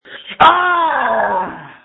• When you call, we record you making sounds. Hopefully screaming.
You might be unhappy, terrified, frustrated, or elated. All of these are perfectly good reasons to call and record yourself screaming.